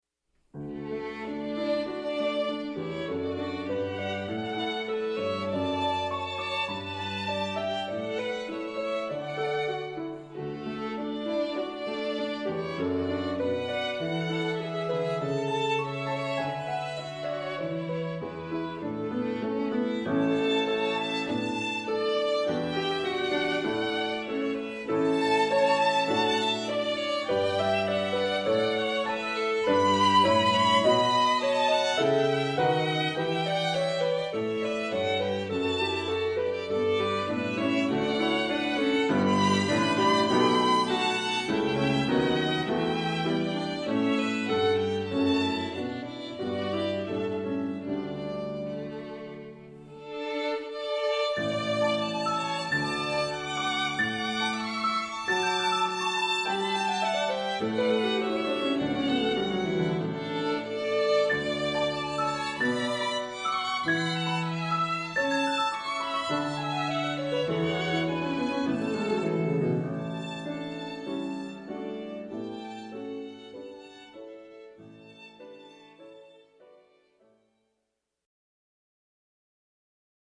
Voicing: 2 Violins